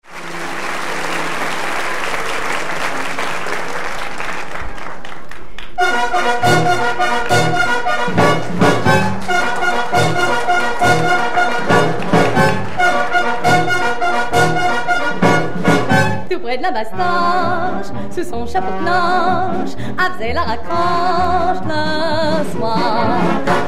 Enregistrement public à Bobino